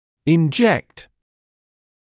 [ɪn’ʤekt][ин’джэкт]инъецировать, впрыскивать; вводить парентерально